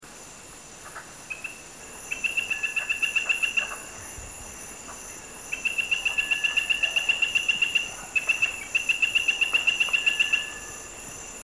Play Especie: Ameerega trivittata Género: Ameerega Familia: Dendrobatidae Órden: Anura Clase: Amphibia Título: Guía sonora de las ranas y sapos de Bolivia.
Localidad: Pingo de Oro, Pando (Bolivia)
21 Epipedobates Trivittatus.mp3